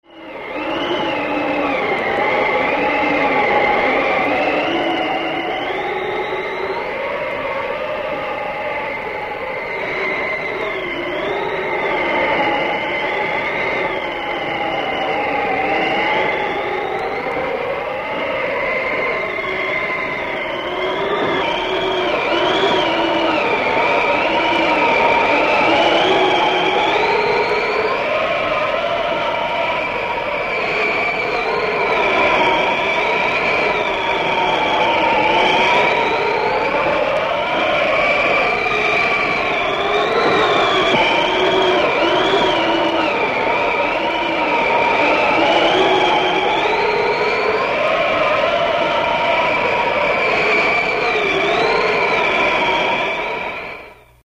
Звуки вьюги, метели
Звук метели в зимнюю пору